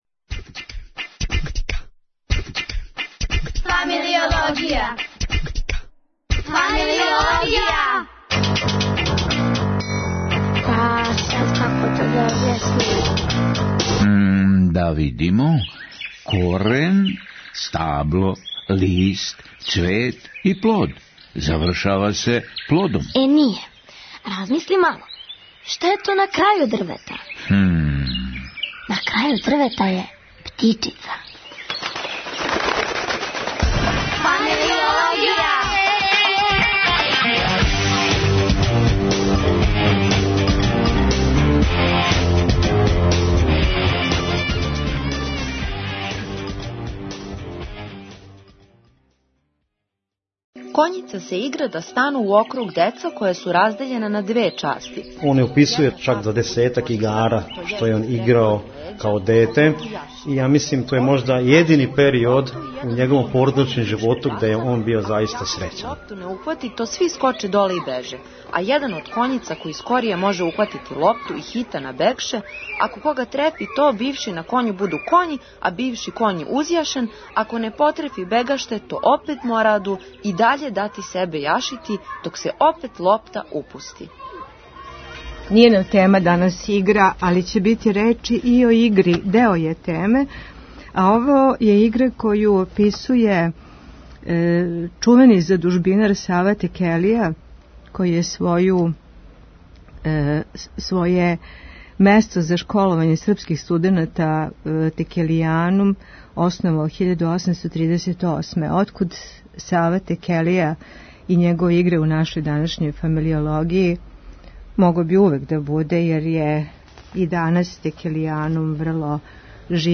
Гошћа у студију је